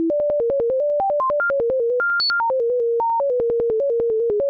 This program finds a factorial. The number to find the factorial of is given at the beginning of the program - in this case Fb-A is 5, so the last note is 120 above A440 (too high to hear, alas).